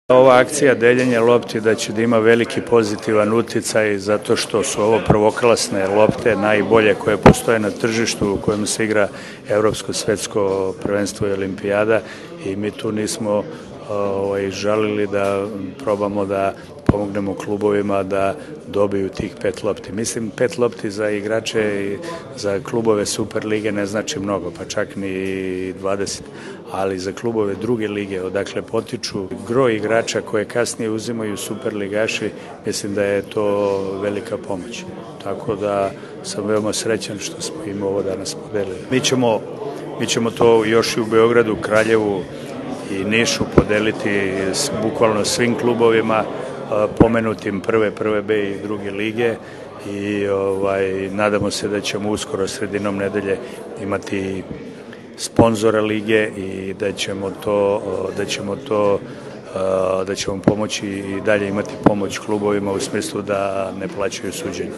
IZJAVA ZORANA GAJIĆA